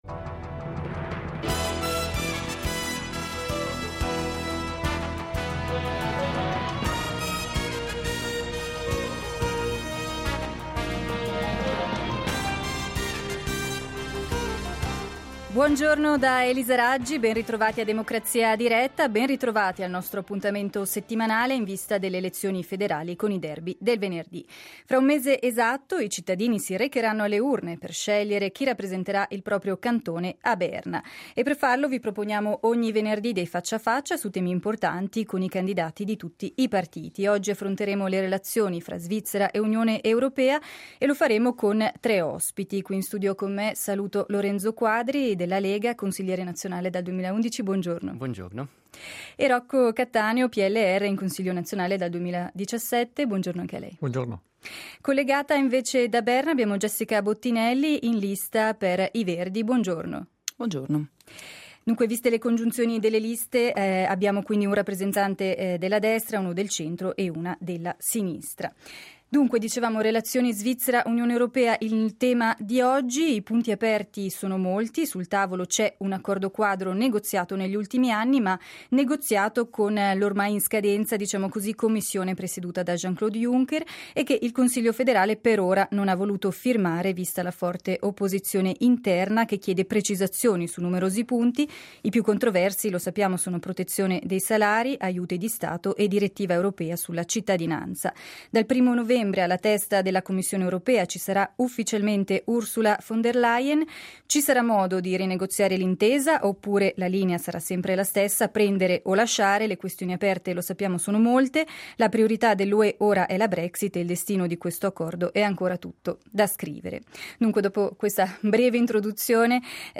Siamo a venerdì 20 settembre, dunque ad un mese esatto dall’appuntamento con le urne, Democrazia diretta ospita, a partire dalle 11:05 due dibattiti.